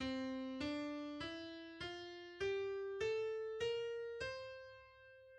Modo mixolídio